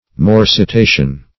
morsitation - definition of morsitation - synonyms, pronunciation, spelling from Free Dictionary
Morsitation \Mor`si*ta"tion\